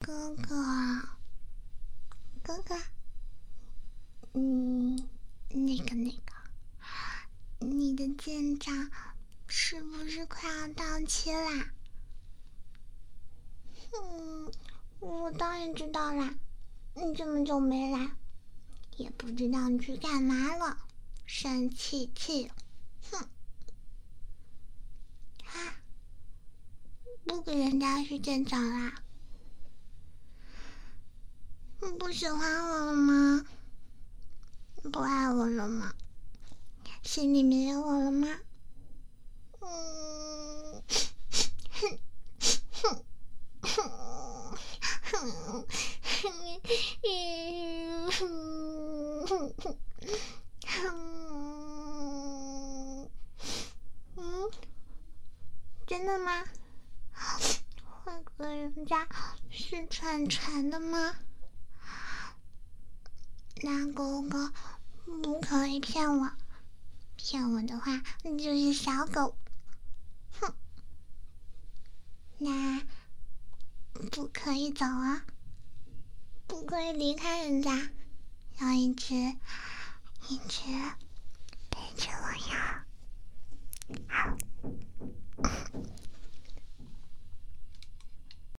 撒娇娇哭唧唧.mp3